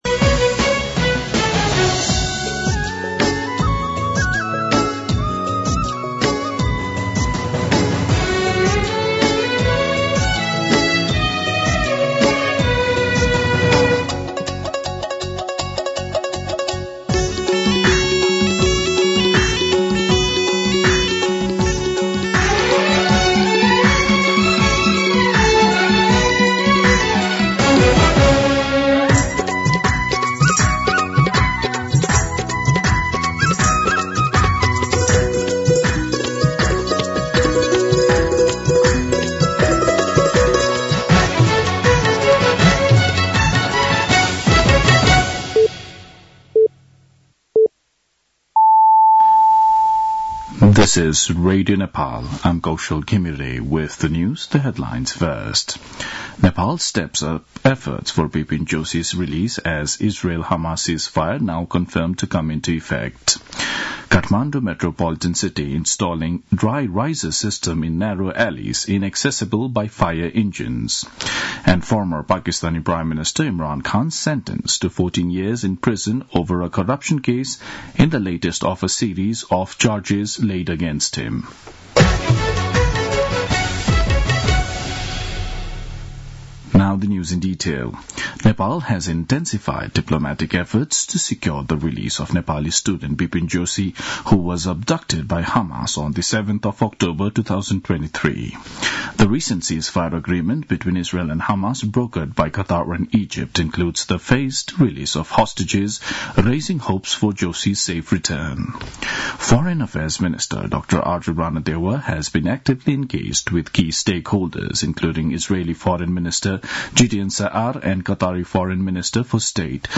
दिउँसो २ बजेको अङ्ग्रेजी समाचार : ६ माघ , २०८१
2-pm-English-News-1.mp3